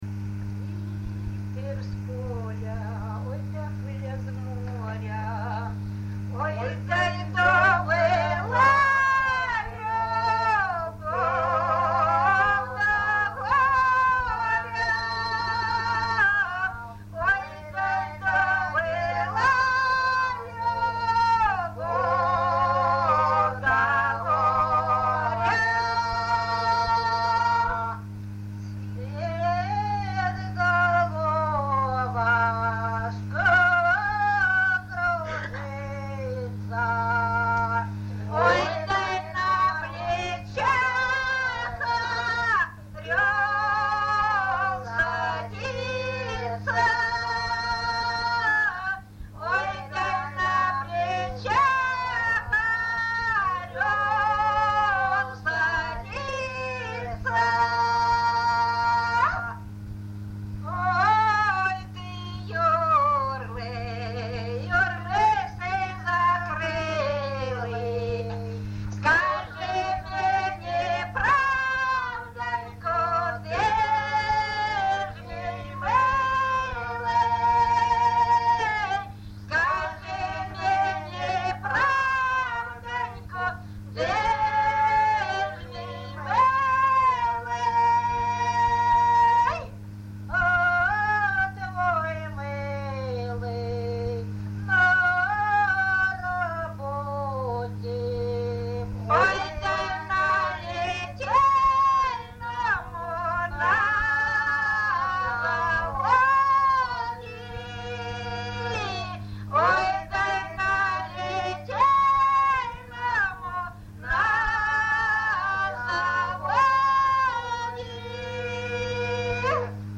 ЖанрПісні з особистого та родинного життя
Місце записус. Бузова Пасківка, Полтавський район, Полтавська обл., Україна, Полтавщина